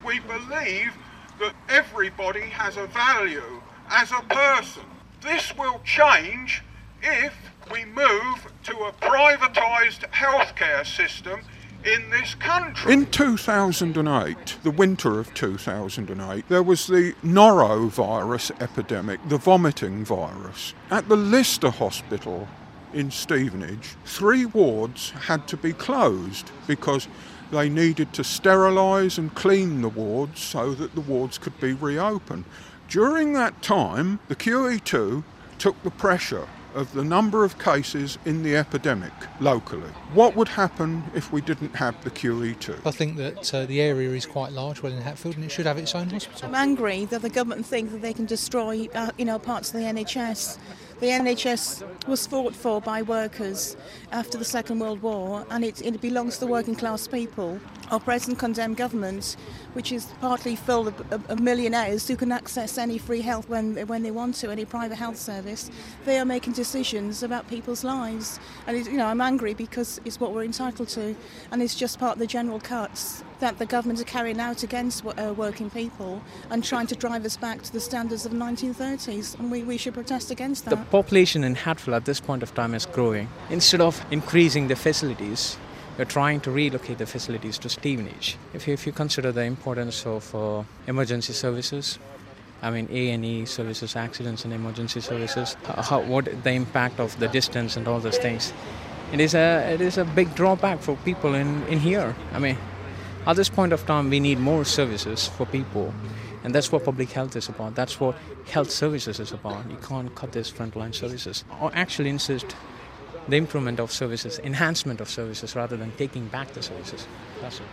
Hertfordshire residents have voiced their opposition to plans to demolish the QE2 Hospital in Welwyn Garden City. Hear from the protesters...